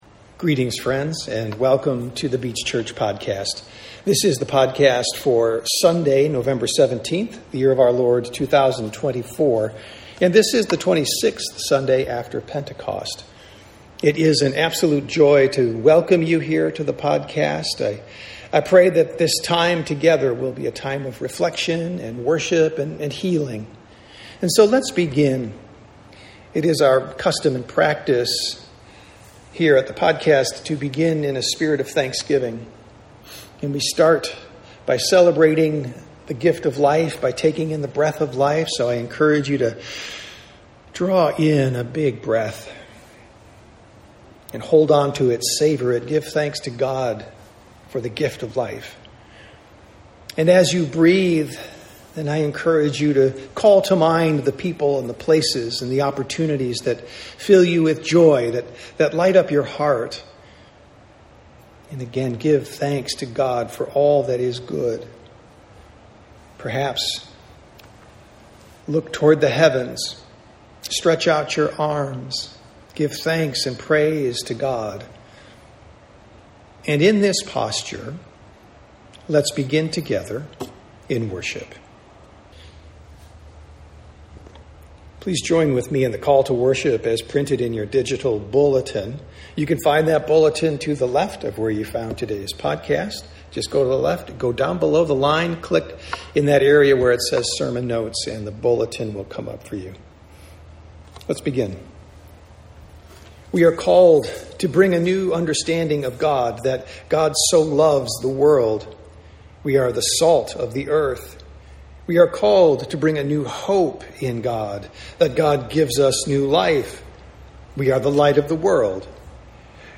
Sermons | The Beach Church
Sunday Worship - November 17, 2024